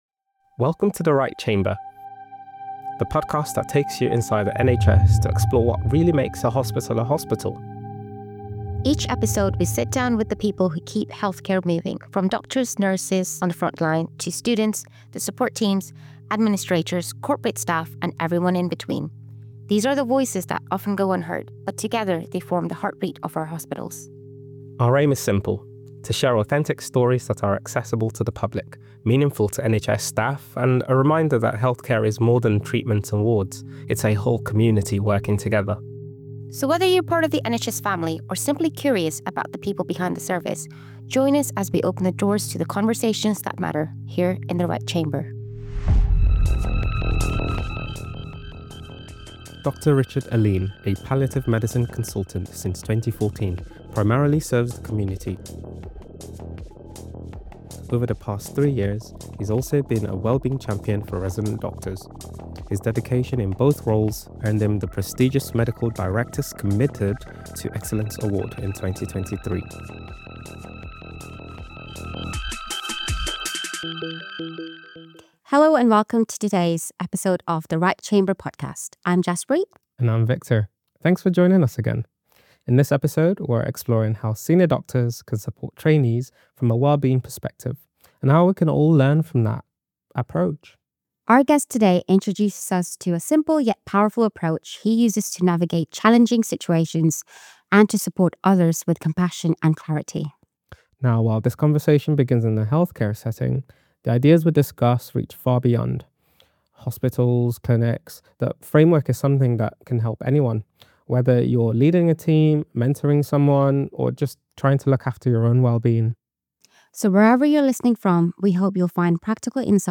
a palliative medicine consultant and Wellbeing Champion for resident doctors